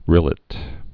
(rĭlĭt)